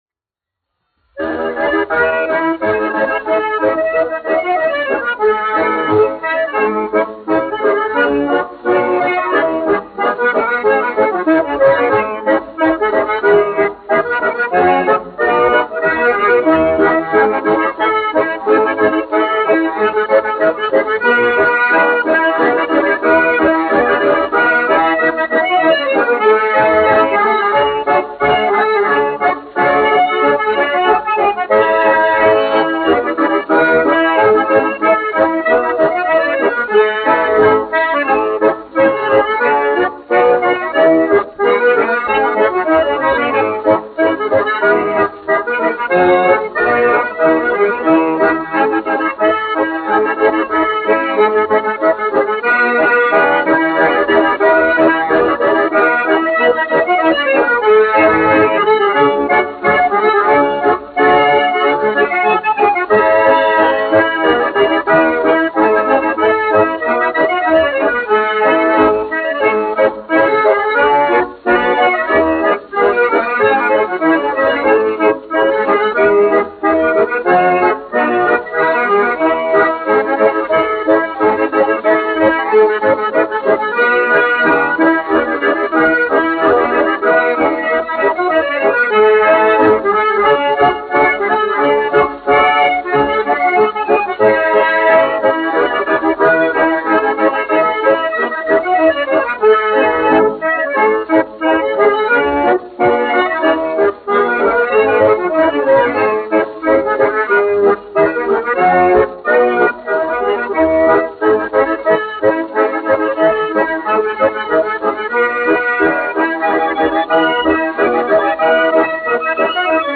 1 skpl. : analogs, 78 apgr/min, mono ; 25 cm
Sarīkojumu dejas
Ermoņikas